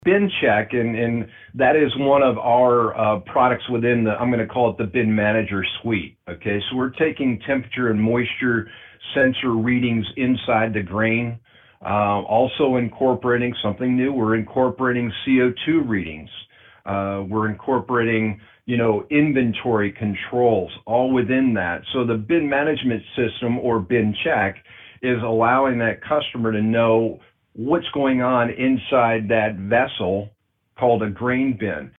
(FULL INTERVIEW BELOW)